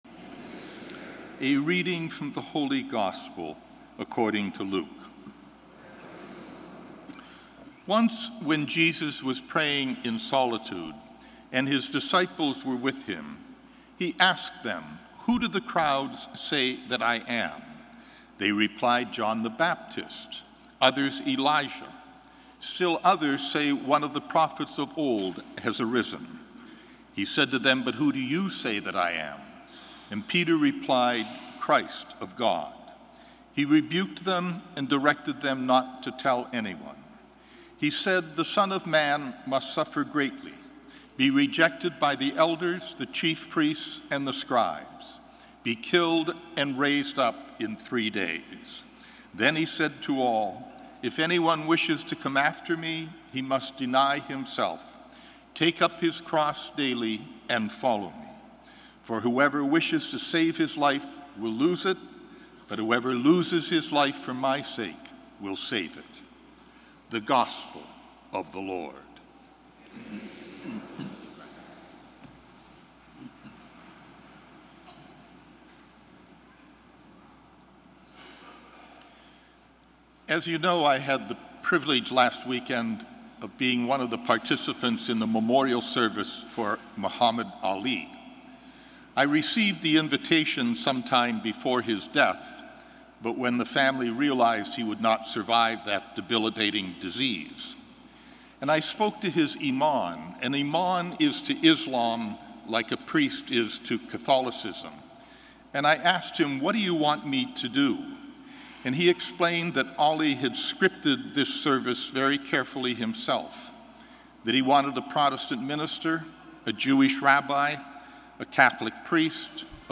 Gospel & Homily June 19, 2016